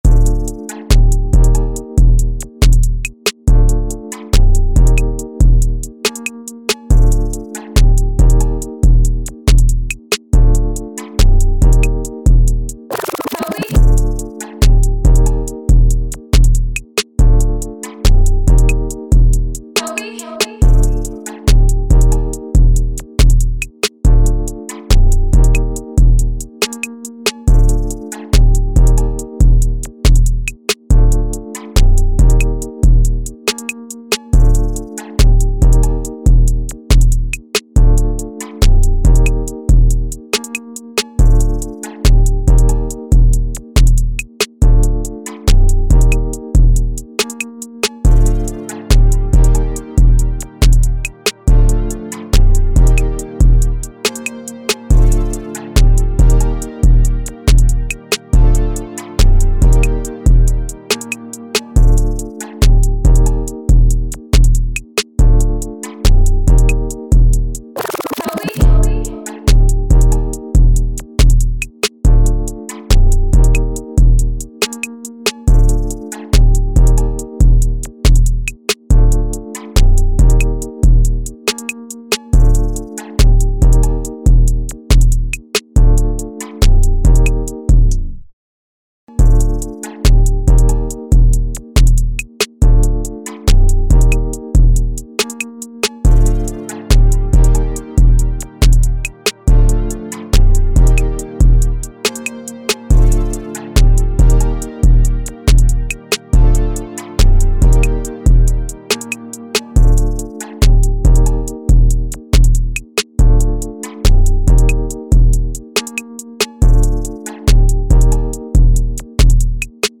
Rap Instrumentals